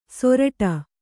♪ soraṭa